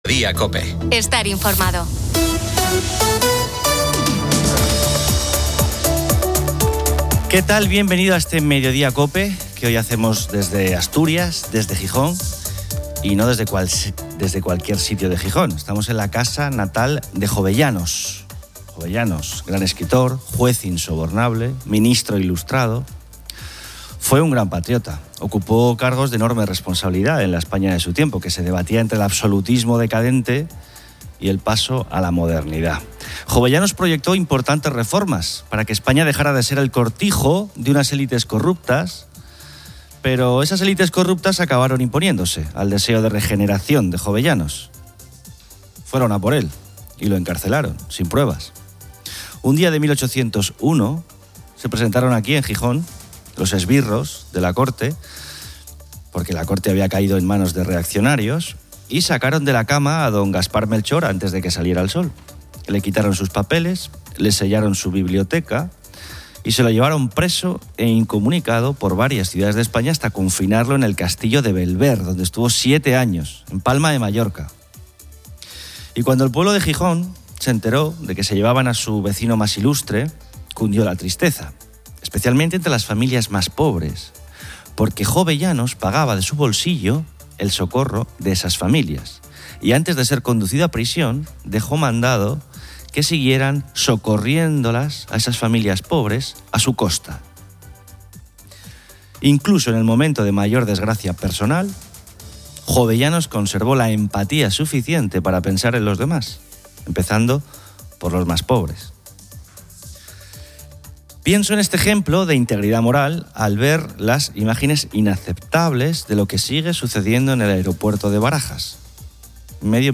Bienvenido a este mediodía cope que hoy hacemos desde Asturias, desde Gijón y no desde cualquier sitio de Gijón. Estamos en la casa natal de Jovellanos.